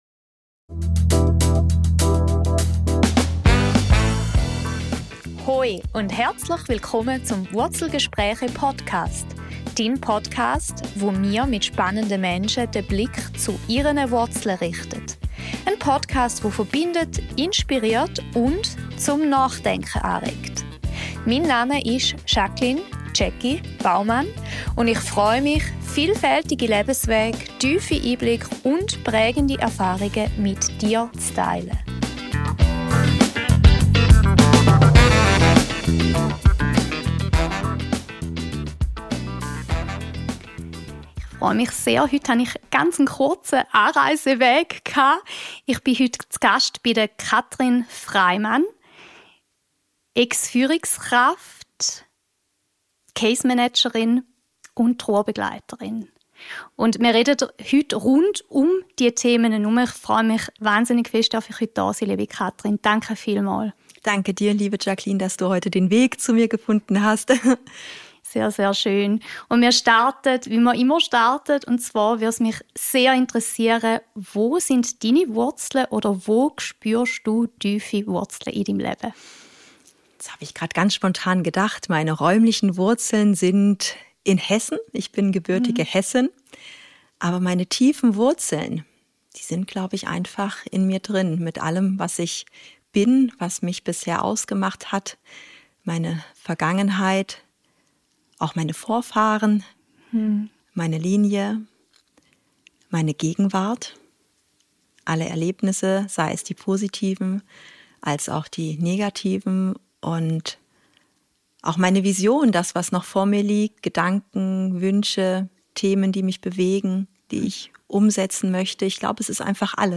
Wir sprechen über die verschiedenen Gesichter der Trauer, darüber, warum Tod in unserer Gesellschaft noch immer ein Tabuthema ist, und wie wir lernen können, offener, natürlicher und menschlicher damit umzugehen – sowohl im privaten Umfeld als auch im Berufsalltag. Ein Gespräch über Mut, Mitgefühl und die Kraft, aus der Trauer heraus wieder ins Leben zu finden.